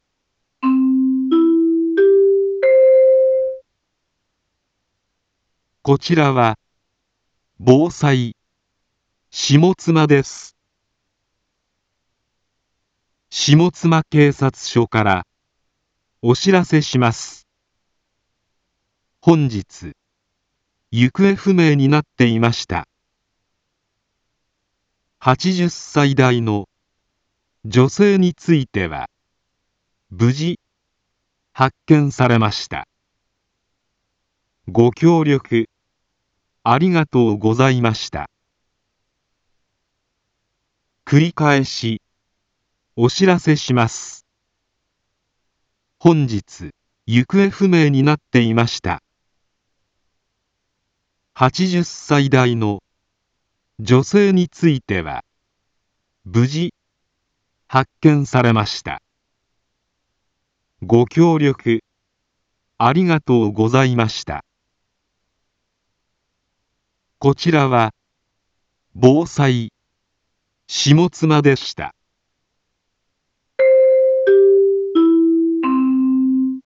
一般放送情報
Back Home 一般放送情報 音声放送 再生 一般放送情報 登録日時：2025-03-14 19:46:35 タイトル：行方不明者発見報 インフォメーション：こちらは、防災、下妻です。